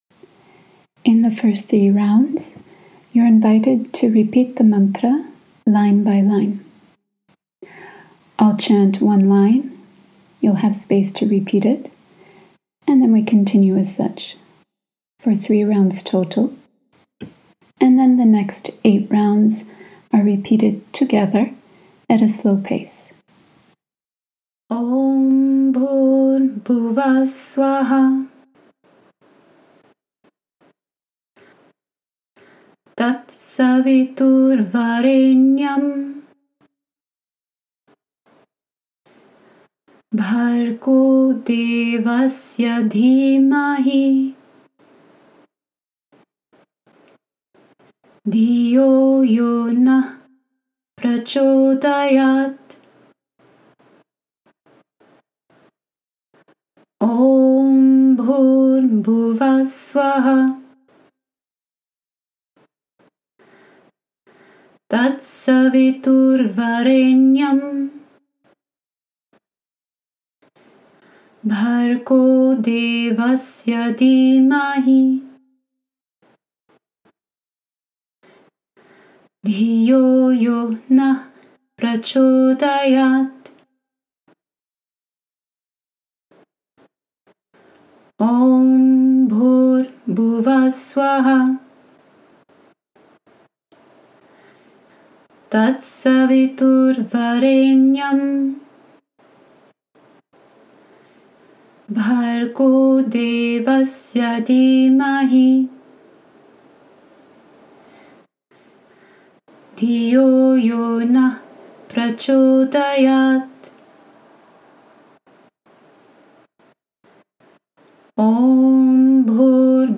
Beginner-friendly version: slow & steady